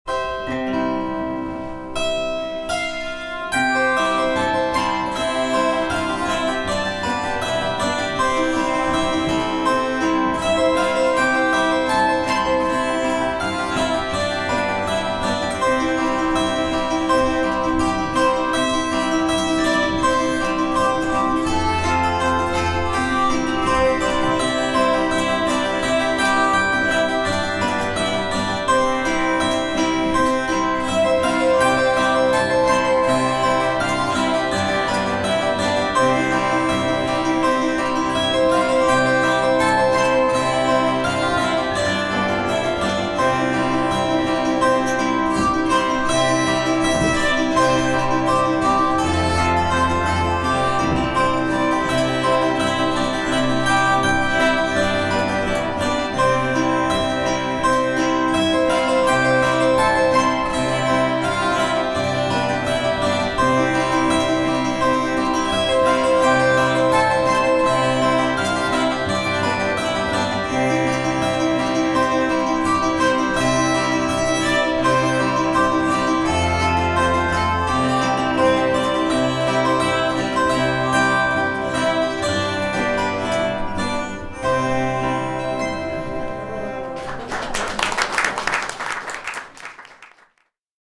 Recorded October 17, 2004 at Wylie House Museum, Bloomington, Indiana
Phonoharp No. 2 1/4 chord-zither, Bosstone ukelin